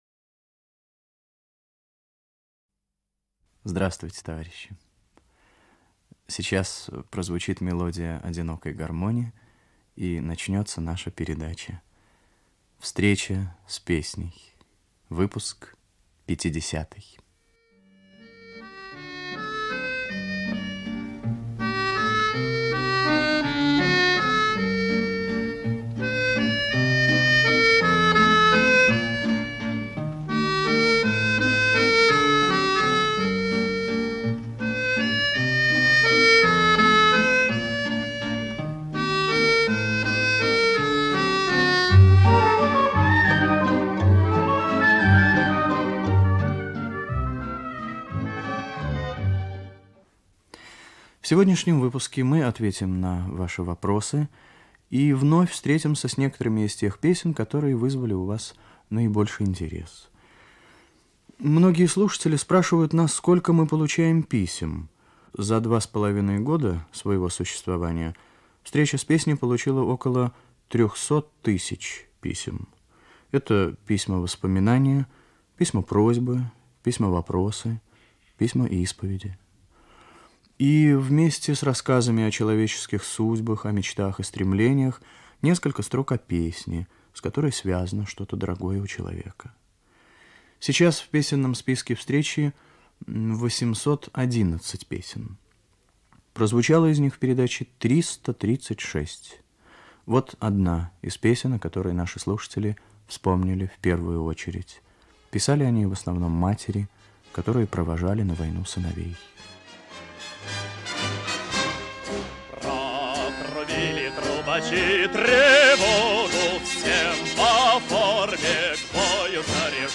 Музыкальная заставка.
Ведущий - автор Татарский Виктор.